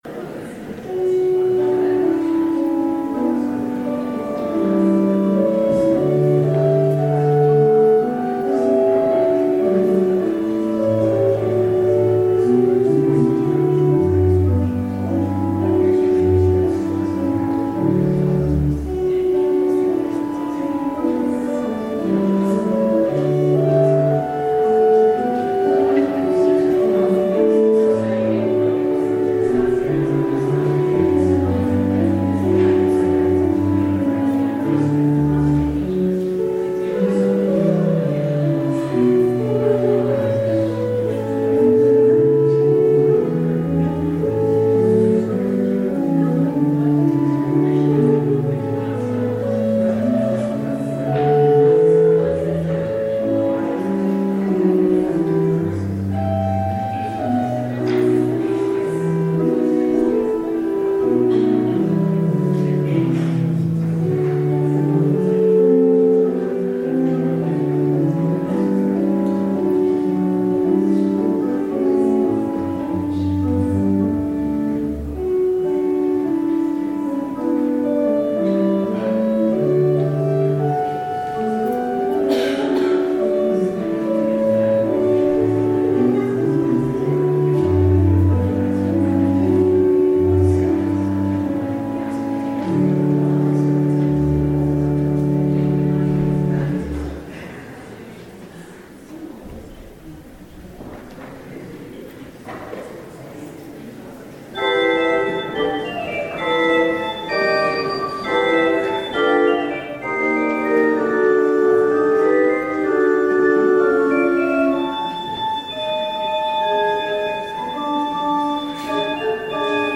Audio recording of the 9:30am service, sermon by the Rt. Rev. Megan Traquair